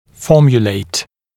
[‘fɔːmjuleɪt][‘фо:мйулэйт]формулировать, разрабатывать